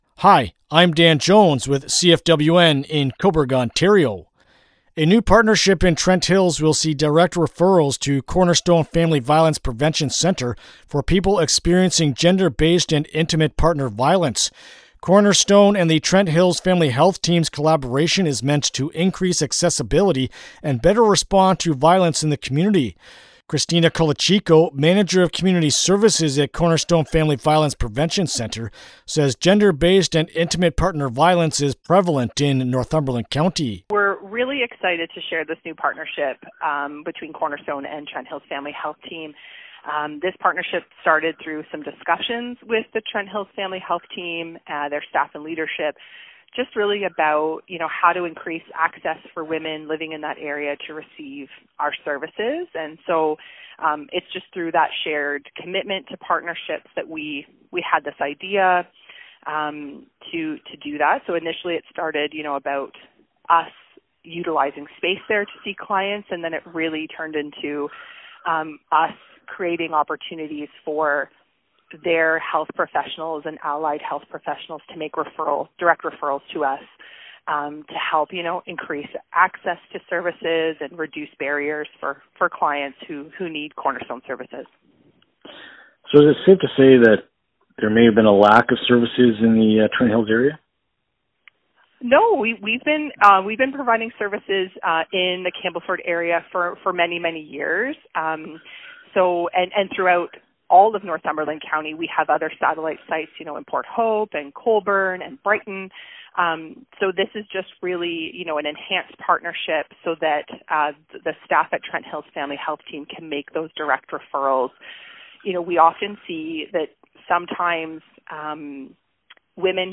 Cornerstone-Partnership-Interview-LJI.mp3